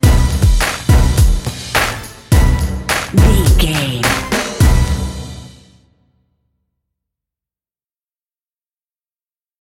Aeolian/Minor
drum machine
synthesiser
funky